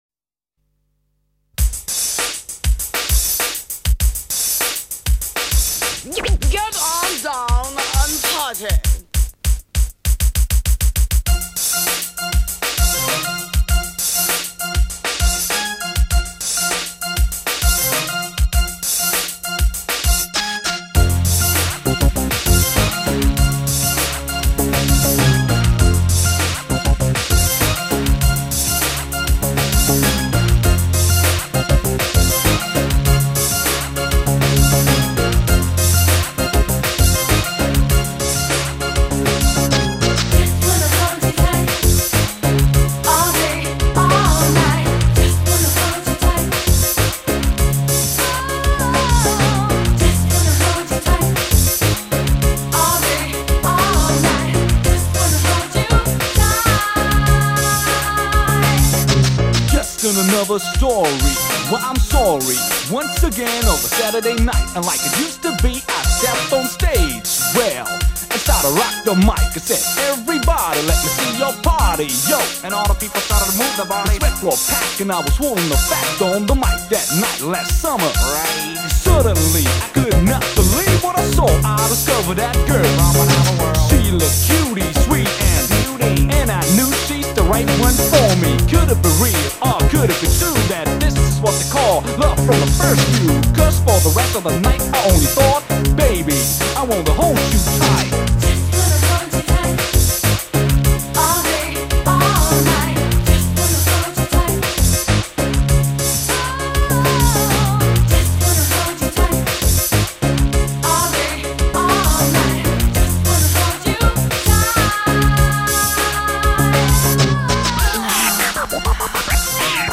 经典迪斯科
此张大碟可谓明星齐集，风格多样。